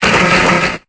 Cri de Solaroc dans Pokémon Épée et Bouclier.